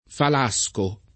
vai all'elenco alfabetico delle voci ingrandisci il carattere 100% rimpicciolisci il carattere stampa invia tramite posta elettronica codividi su Facebook falasco [ fal #S ko ] s. m. (bot.); pl. ‑schi — sim. i cogn.